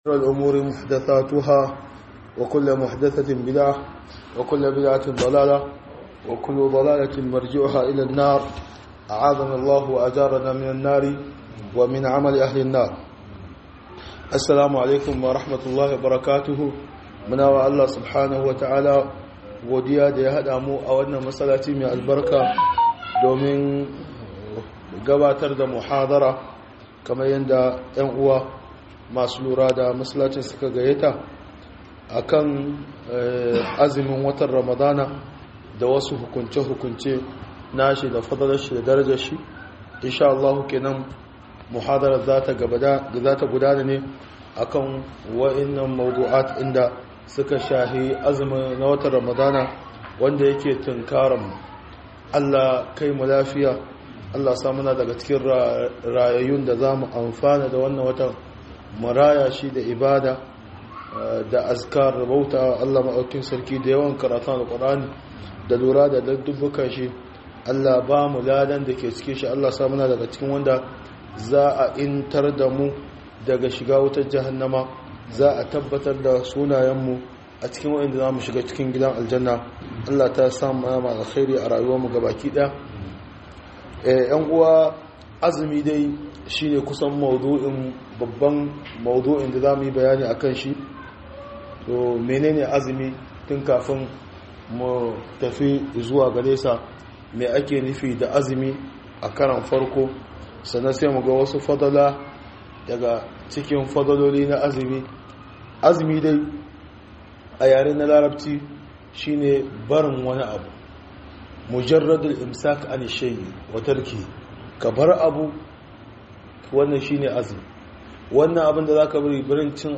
006-Falalar Azumi - MUHADARA